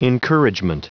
Prononciation du mot encouragement en anglais (fichier audio)
Prononciation du mot : encouragement